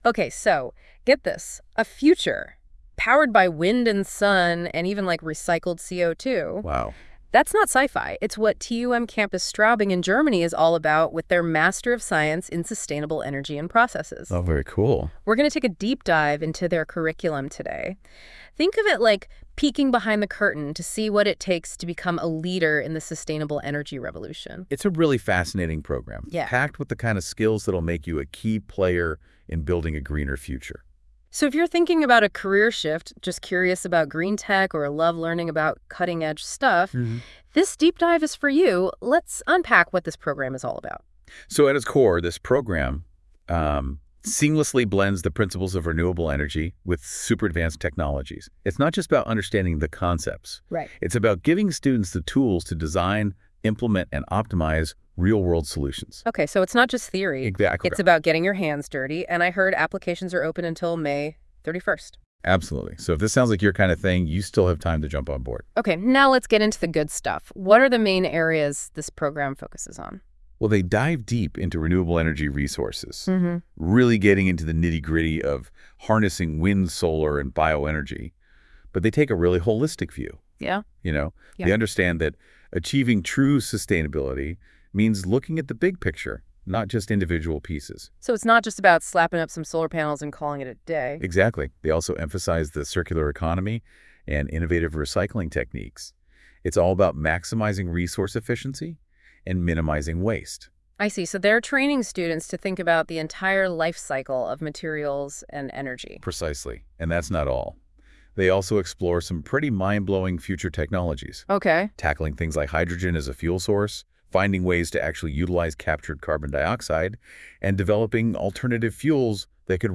Interview to the TUM Master Sustainable Energy and Processes at TUMCS in English
Master_Sustainable_Energy_and_Processes_Interview.wav